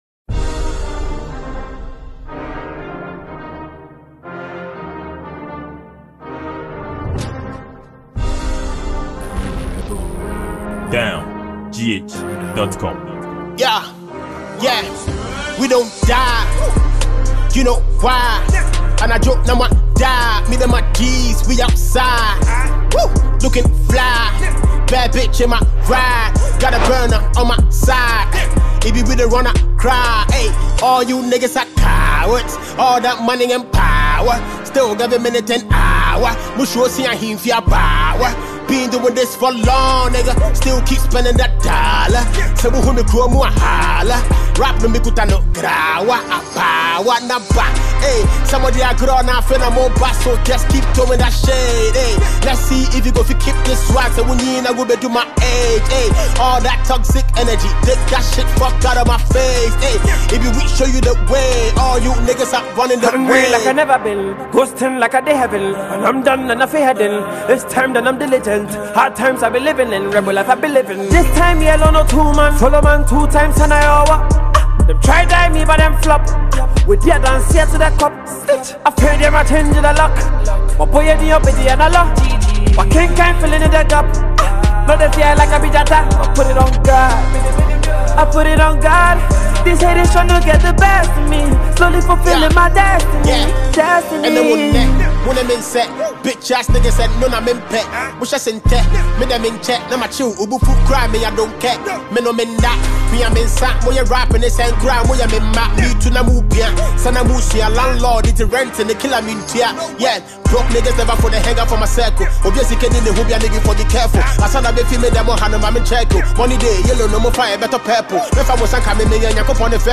Genre: Hiphop